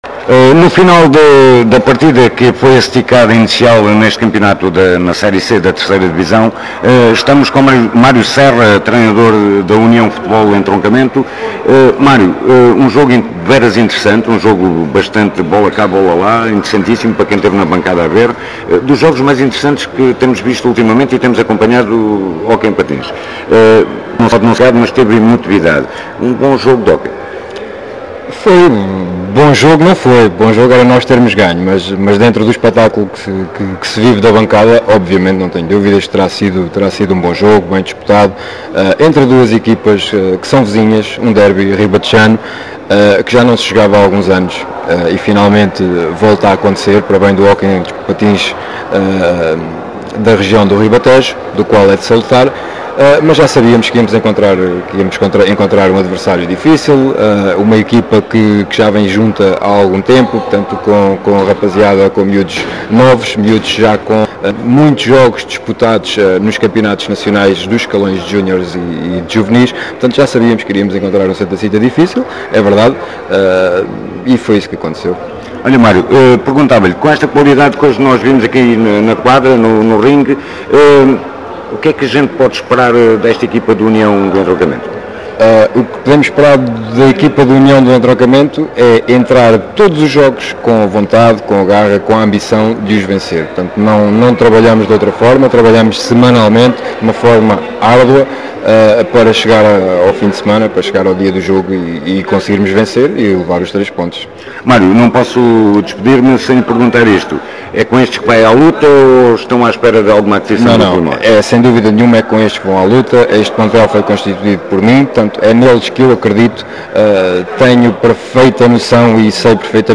No final estivemos à conversa com ambos os treinadores: